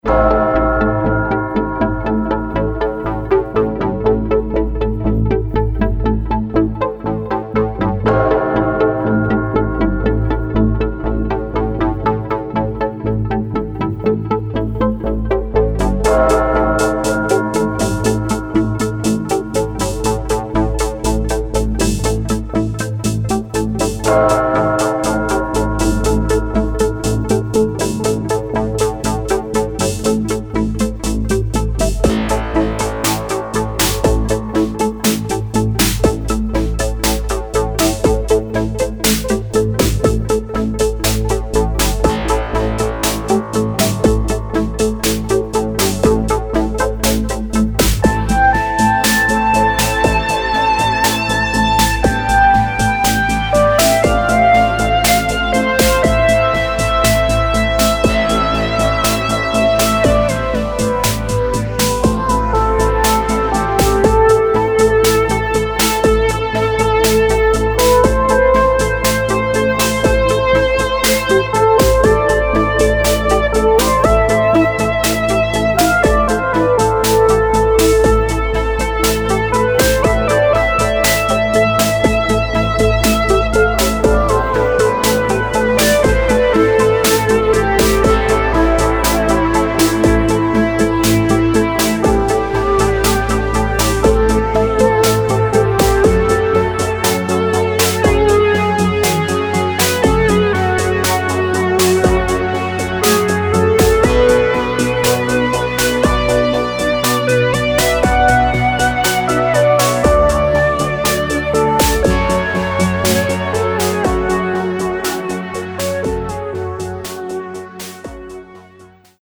Style: Cinematic Electronic